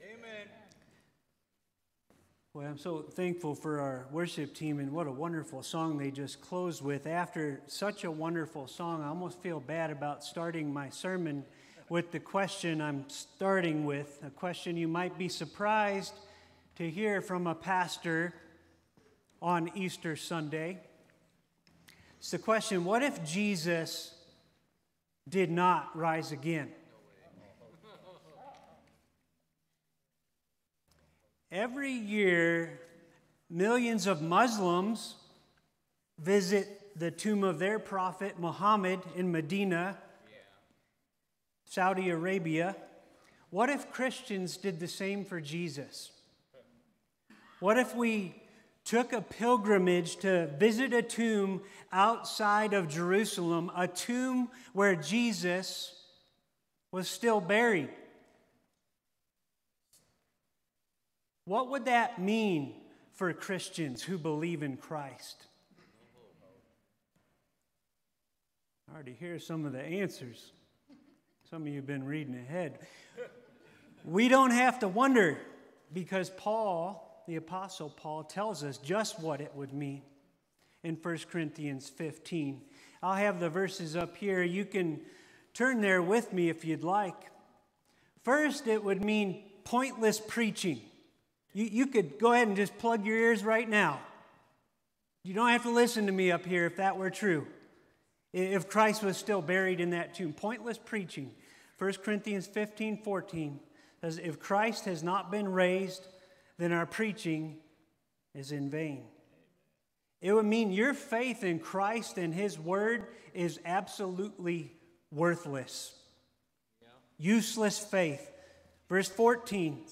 Unusual title for an Easter sermon.
You’ll also hear the testimony of two lives recently redeemed by the Risen Christ.